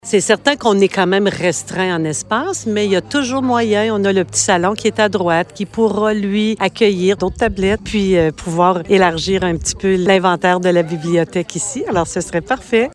Michel Houde, conseiller à la Municipalité et responsable du dossier, parle de l’importance de finalement retrouver une bibliothèque sur le territoire :